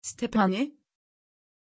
Pronunciation of Stéphanie
pronunciation-stéphanie-tr.mp3